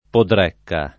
[ podr $ kka ]